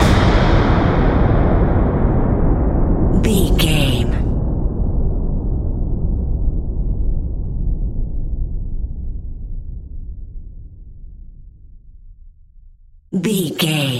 Big Mechanical Hit
Sound Effects
Atonal
tension
ominous
dark